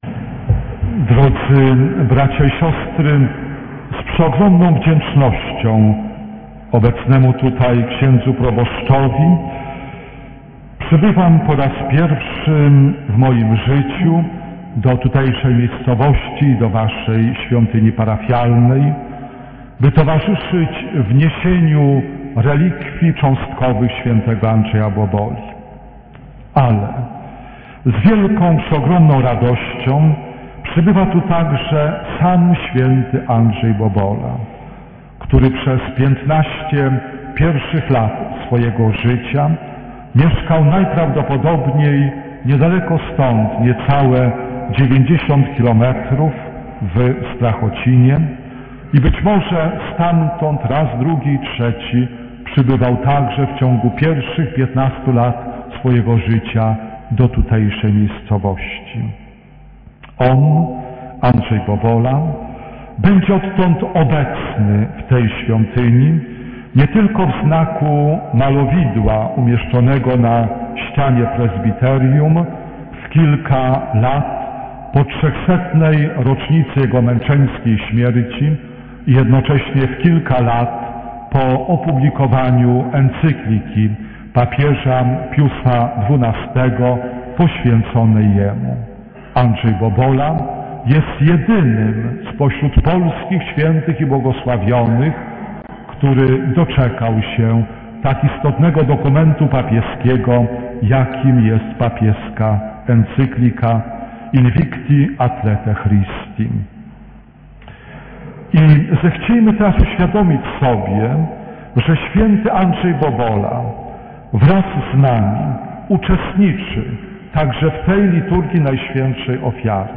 W pierwszą niedzielę Adwentu, 01.12.2024 roku, do naszej parafii uroczyście wprowadziliśmy relikwie świętego Andrzeja Boboli, Patrona Polski.